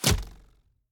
Bow Blocked 1.ogg